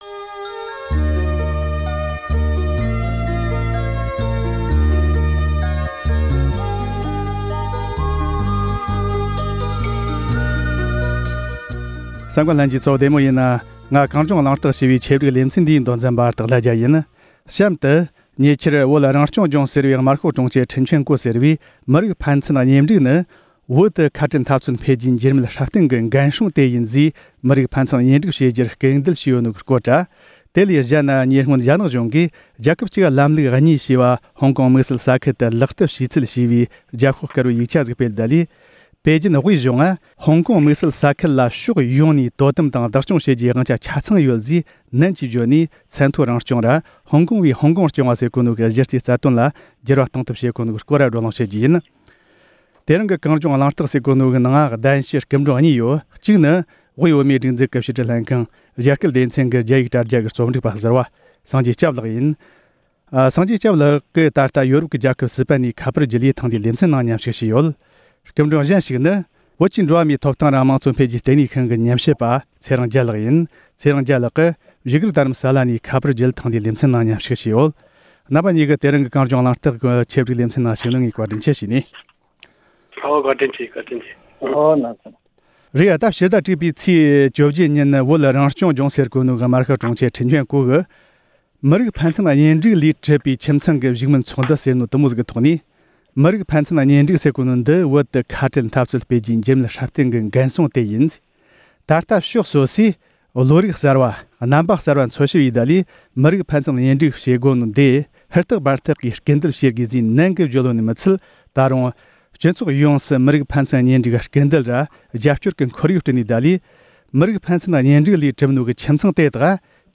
ཧོང་ཀོང་བས་ཧོང་ཀོང་སྐྱོང་བ་ཞེས་པའི་གཞི་རྩའི་རྩ་དོན་ལ་འགྱུར་བ་གཏོང་ཐབས་ བྱེད་བཞིན་པའི་སྐོར་ལ་བགྲོ་གླེང་ཞུས་པ་ཞིག་གསན་རོགས་གནང་།།